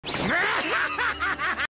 Play, download and share kuroo hyena laugh original sound button!!!!
kuroo-hyena-laugh.mp3